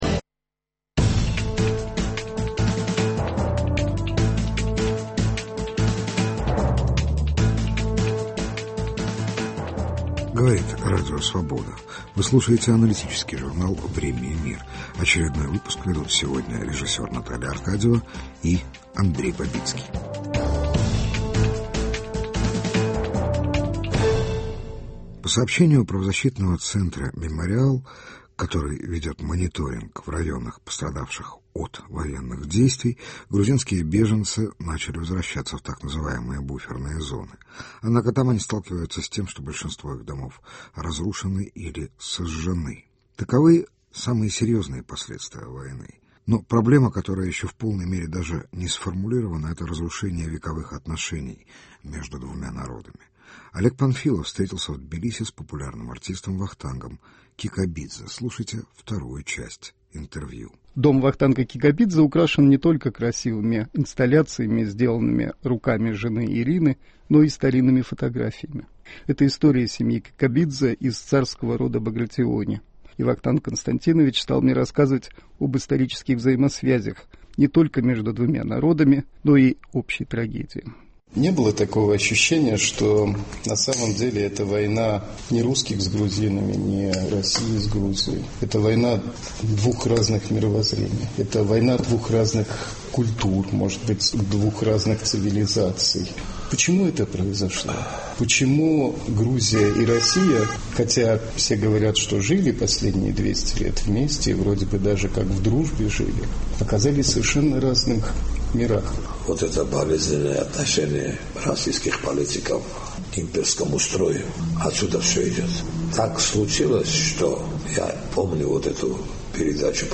Новое лицо Грузии: 2 часть интервью с Вахтангом Кикабидзе.